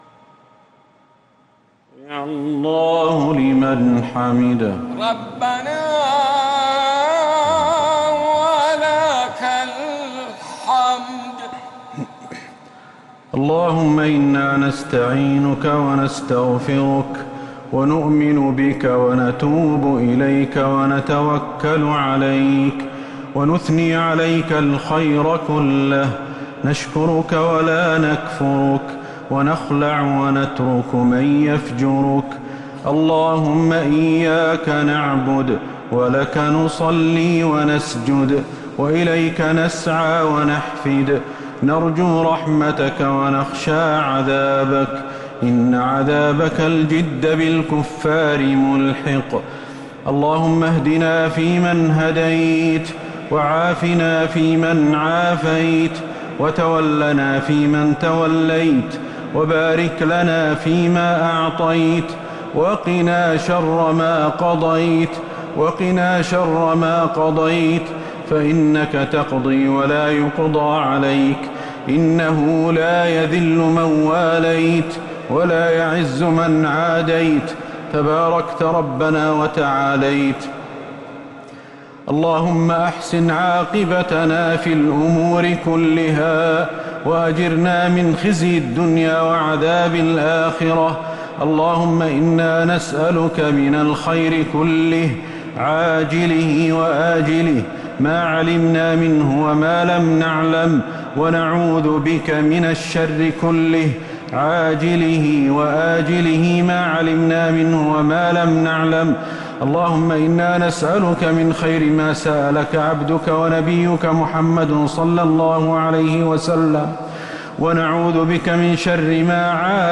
دعاء القنوت ليلة 4 رمضان 1447هـ | Dua 4th night Ramadan 1447H > تراويح الحرم النبوي عام 1447 🕌 > التراويح - تلاوات الحرمين